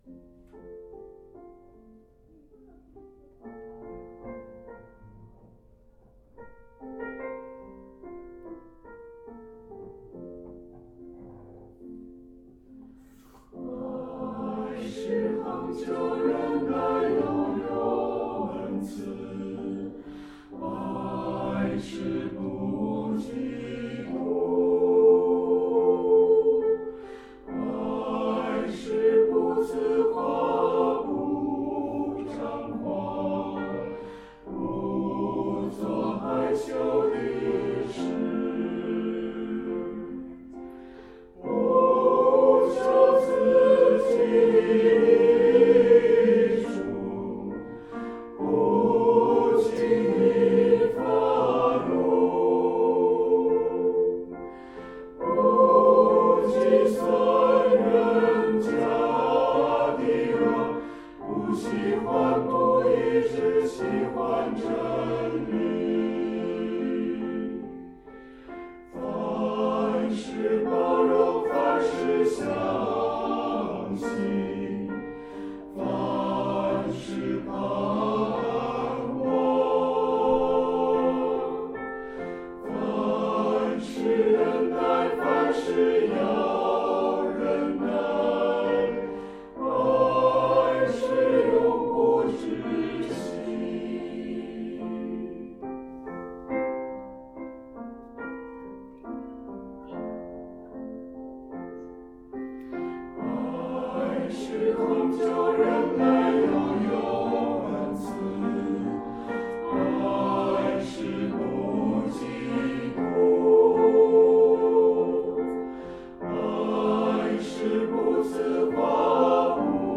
回应诗歌：爱的真谛（151，新264）